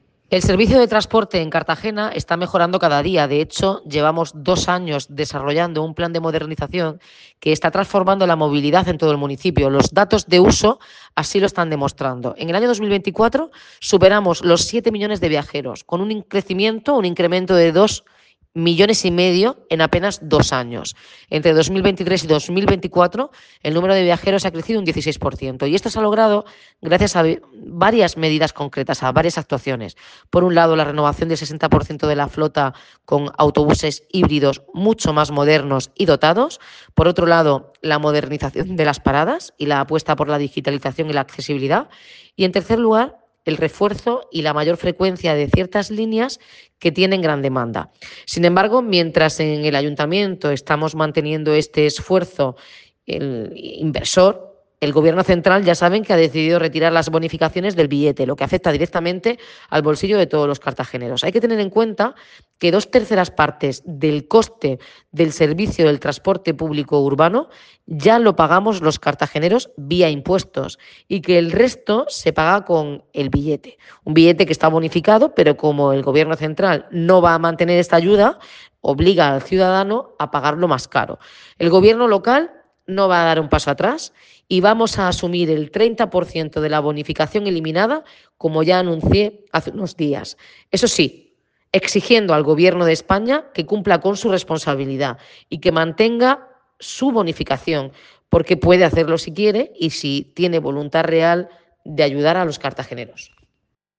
Enlace a Declaraciones de la alcaldesa, Noelia Arroyo, sobre el plan de modernización del transporte